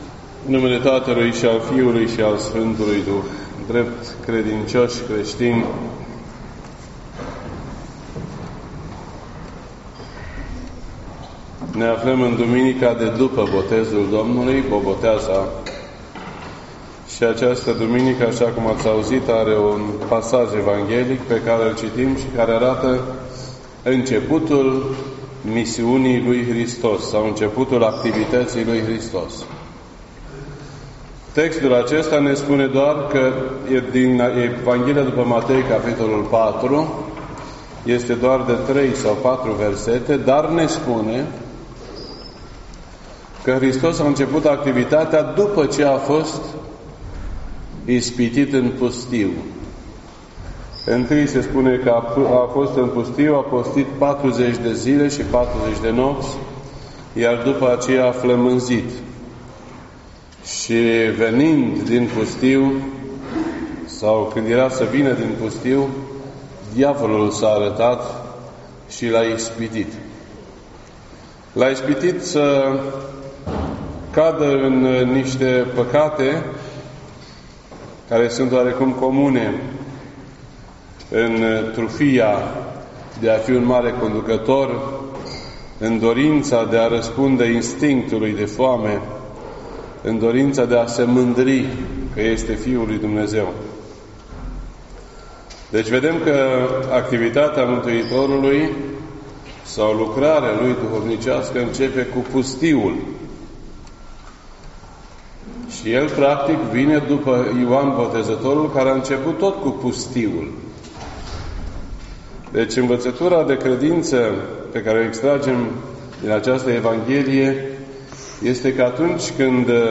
This entry was posted on Sunday, January 13th, 2019 at 12:22 PM and is filed under Predici ortodoxe in format audio.